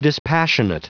Prononciation du mot dispassionate en anglais (fichier audio)
Prononciation du mot : dispassionate